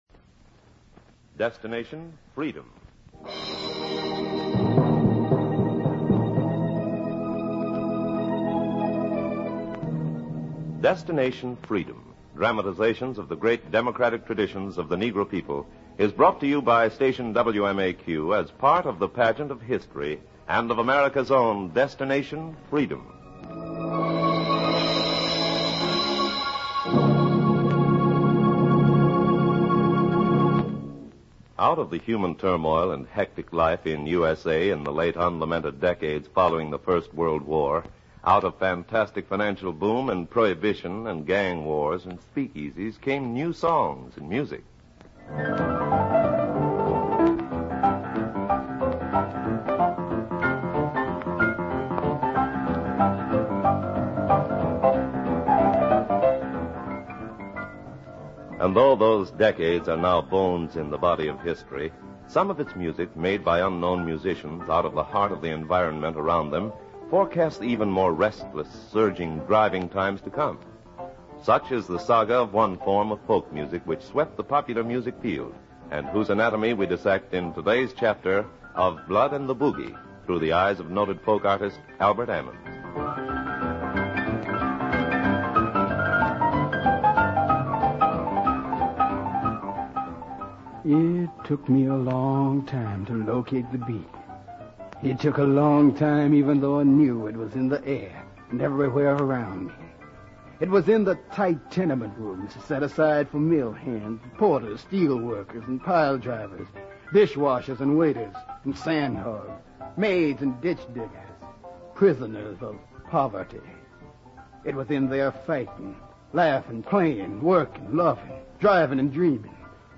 "Of Blood and the Boogie" is an episode from the "Destination Freedom" series that aired on October 16, 1949. This series was known for its dramatizations of the lives and struggles of notable African Americans, highlighting their contributions to society and the fight against racial discrimination.